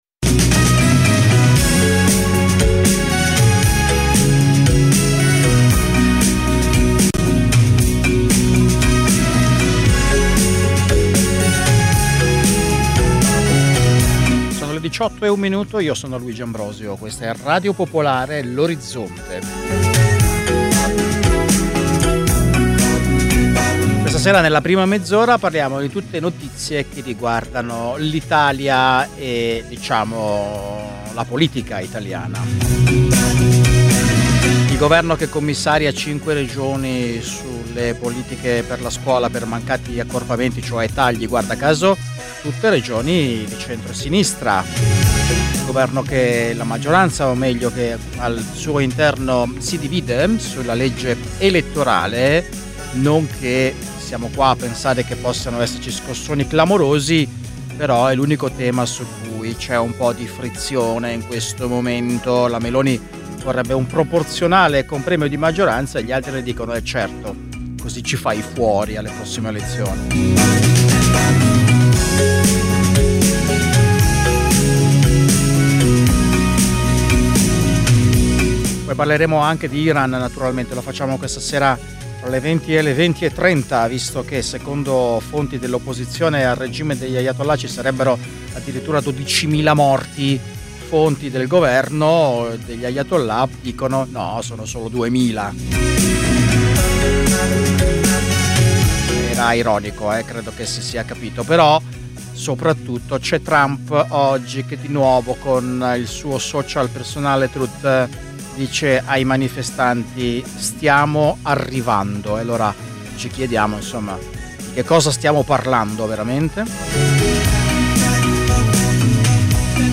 Dalle 18 alle 19 i fatti dall’Italia e dal mondo, mentre accadono. Una cronaca in movimento, tra studio, corrispondenze e territorio. Senza copioni e in presa diretta.